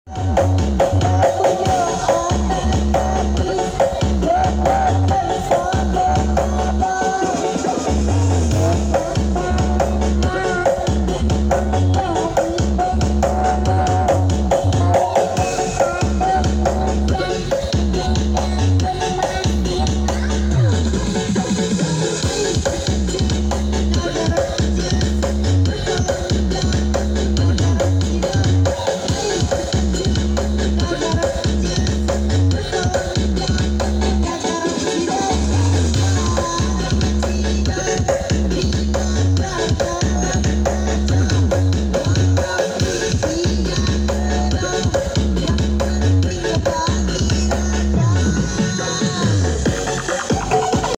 karnaval Purwodadi bersama dinas perpustakaan#beranda